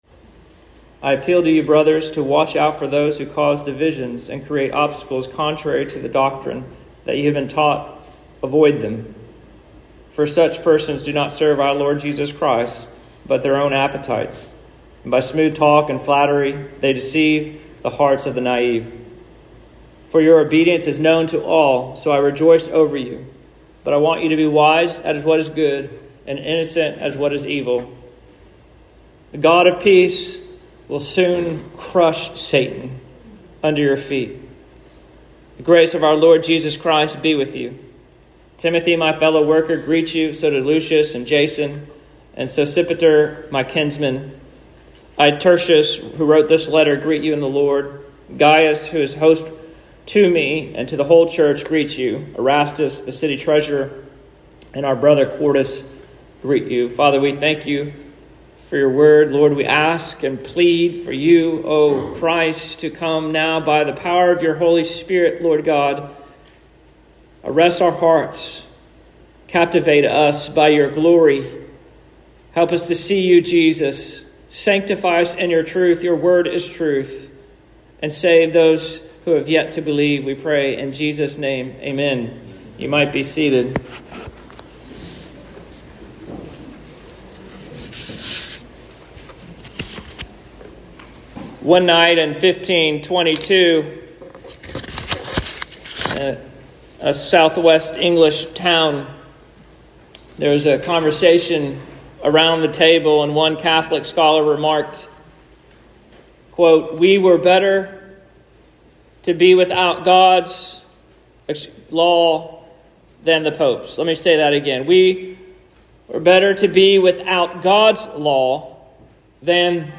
Sermons | Christ Community Church